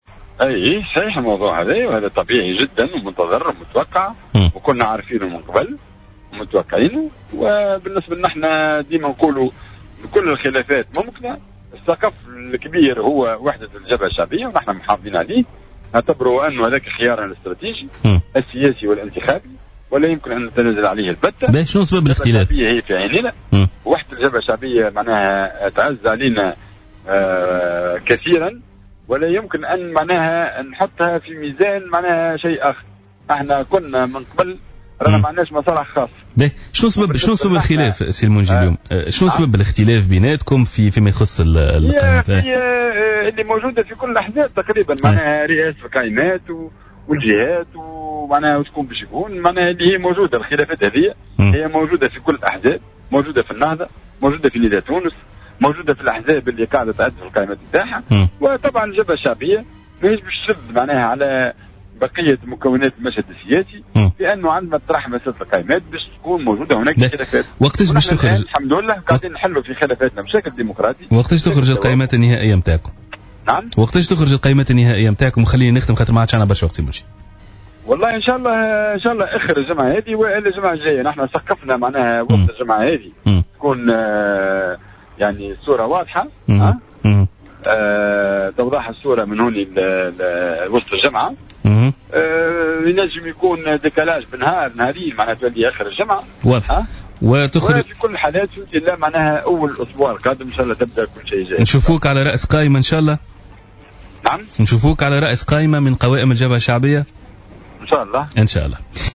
علّق القيادي بحزب الوطنيين الديمقراطيين الموحد،المنجي الرحوي اليوم في برنامج "بوليتيكا" على الأخبار الرائجة حول وجود خلافات داخل الجبهة الشعبية.